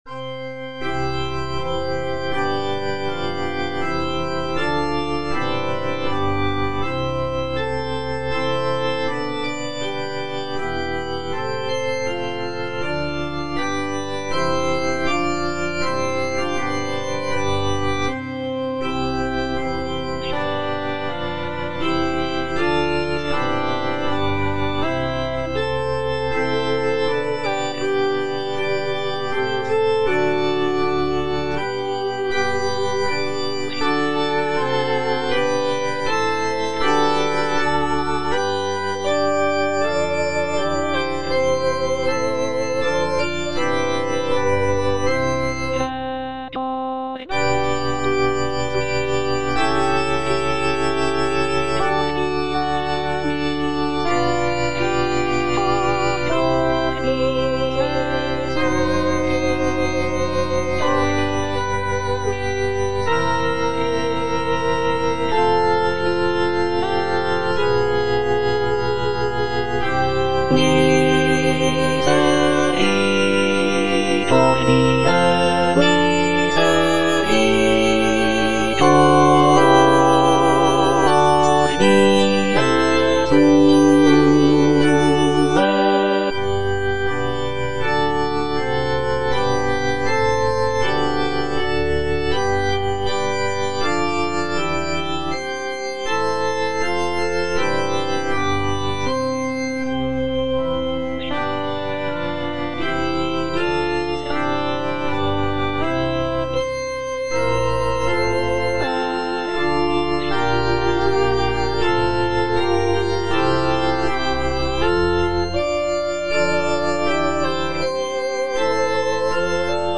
B. GALUPPI - MAGNIFICAT Suscepit Israel (All voices) Ads stop: auto-stop